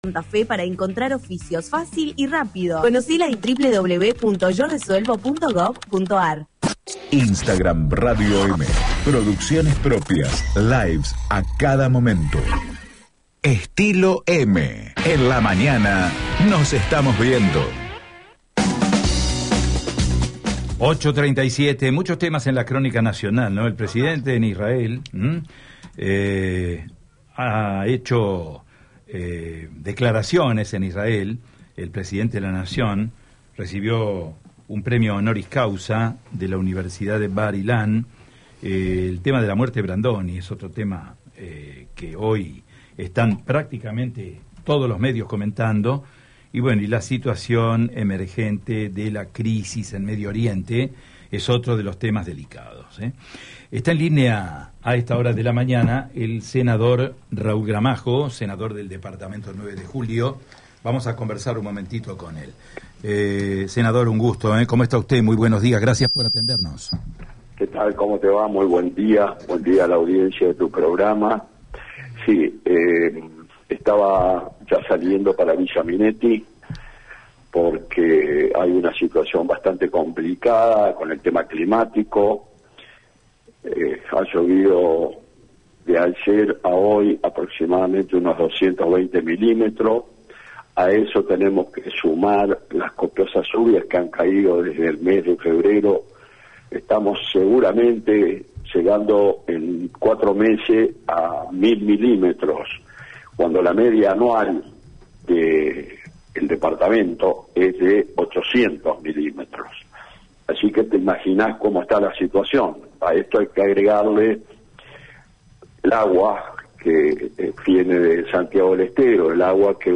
“Hay una situación bastante complicada con el tema climático en Villa Minetti. Han llovido aproximadamente entre 220 y 240 milímetros en este evento”, explicó el legislador en diálogo con EME.
Escuchá la palabra de Raúl Gramajo en EME: